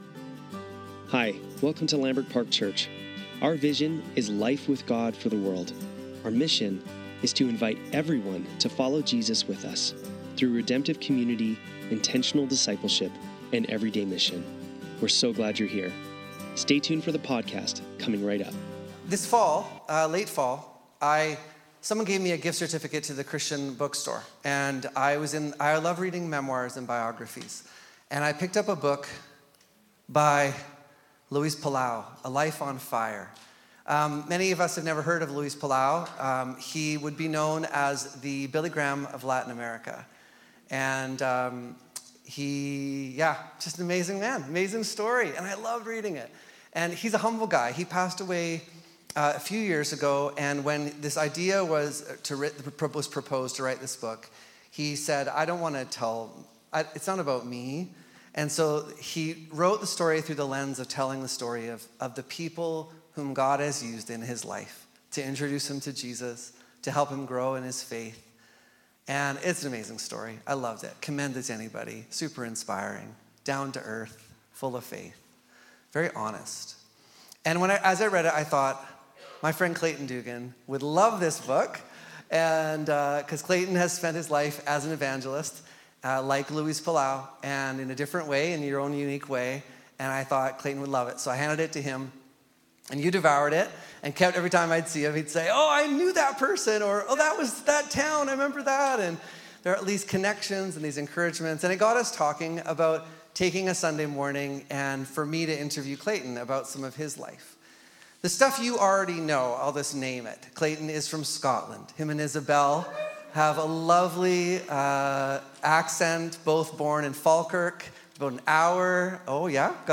Sunday Service - January 19, 2025